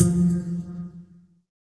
SYN_Pizz5.wav